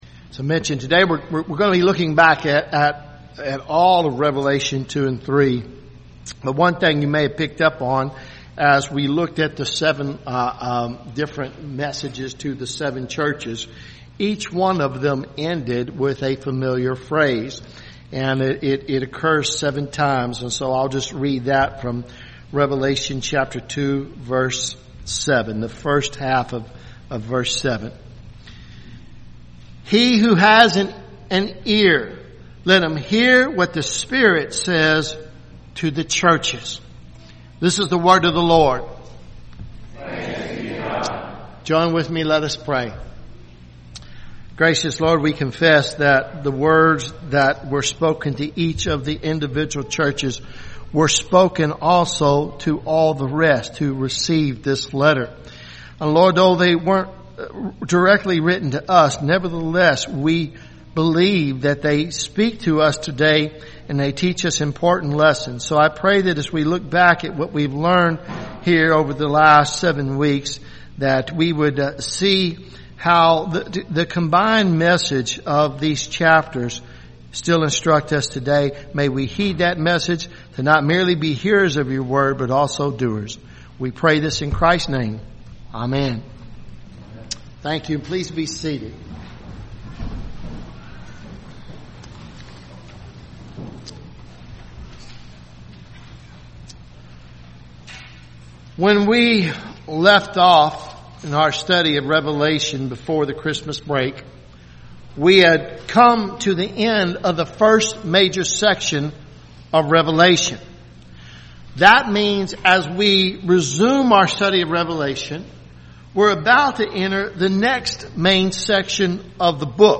Revelation sermon series